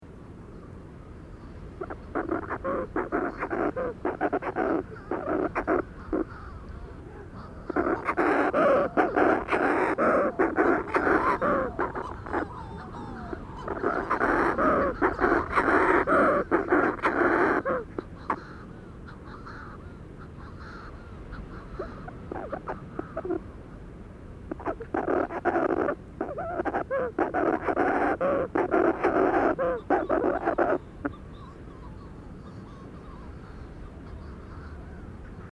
Manx Shearwater Recordings, July 2007, Co. Kerry, Ireland
adult closer + burrows + surf bkgrnd